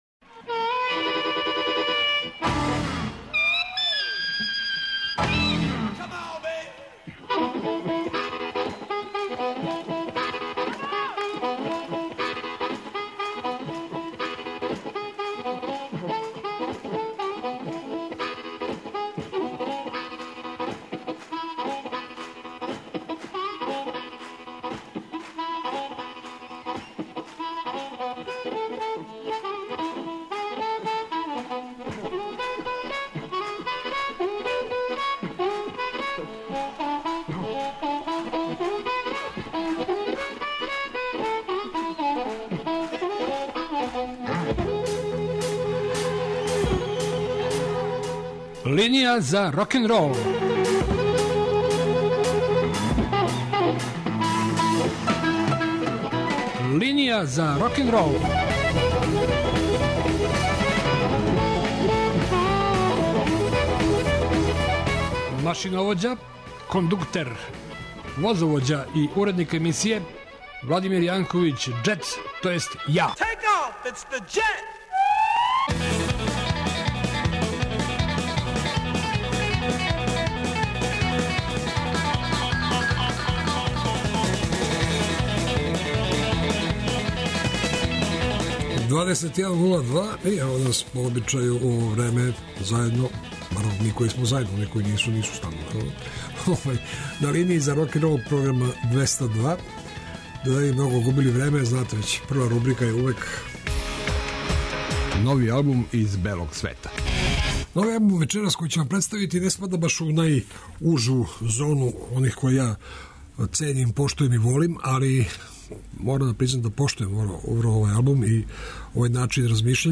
И ове суботе вас очекују стандардне рубрике и много нове музике.